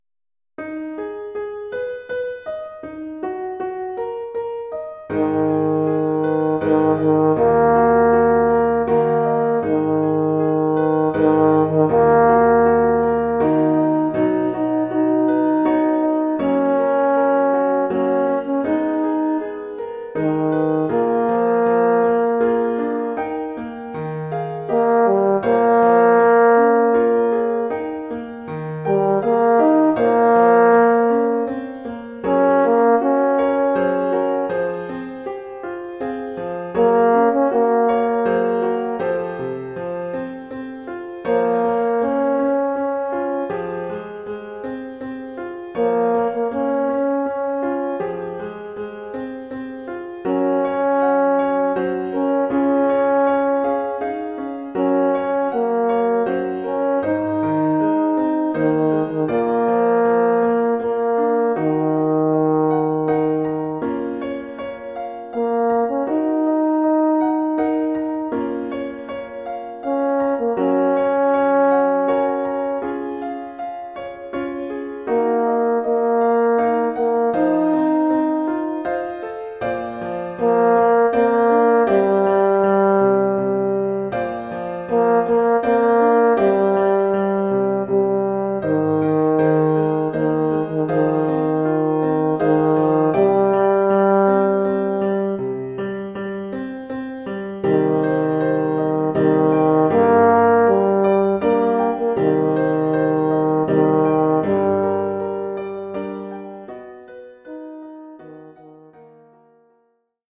Formule instrumentale : Cor naturel mib et piano
Oeuvre pour cor naturel mib et piano.